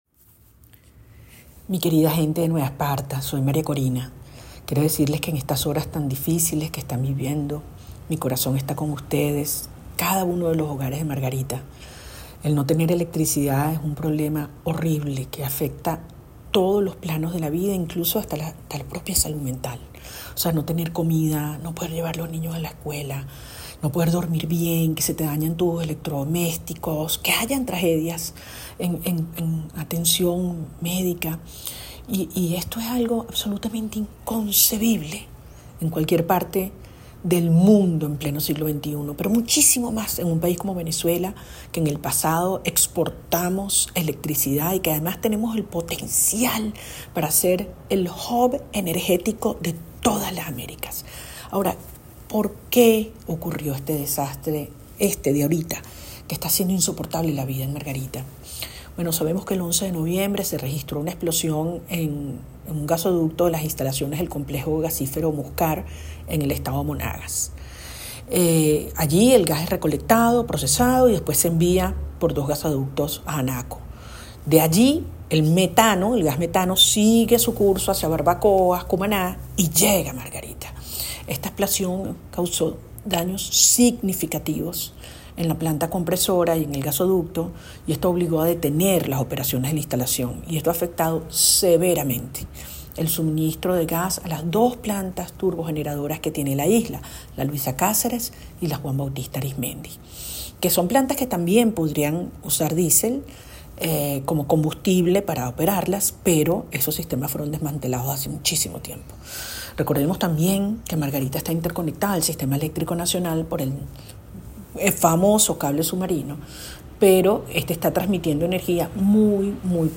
La líder opositora, María Corina Machado, envió un mensaje de solidaridad a todos los venezolanos que viven en el estado Nueva Esparta, ante la crisis eléctrica que están padeciendo, tras la explosión en la Planta de Gas Muscar, en el estado Monagas.